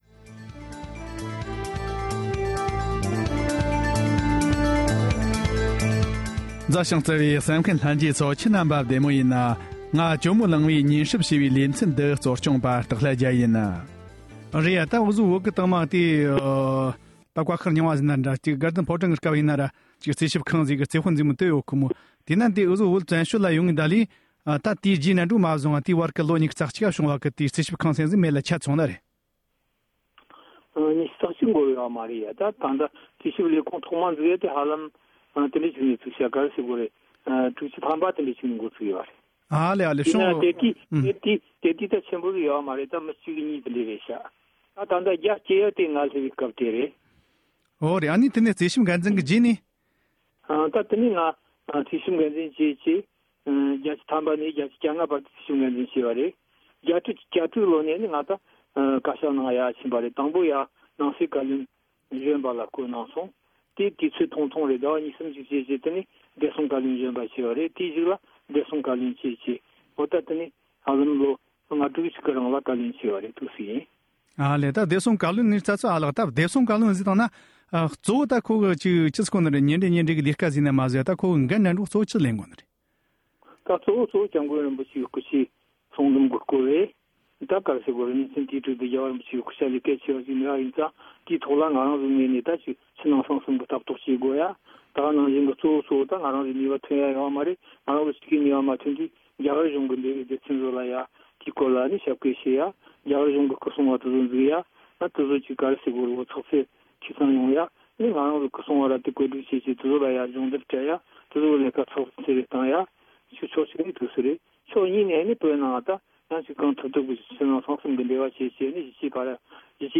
བཀའ་ཟུར་ཨ་ལགས་འཇིགས་མེད་རིན་པོ་ཆེ་མཆོག་གི་སྐུ་ཚེའི་ལོ་རྒྱུས་སྐོར་ལ་བཅར་འདྲི་ཞུས་པའི་དུམ་བུ་གསུམ་པ།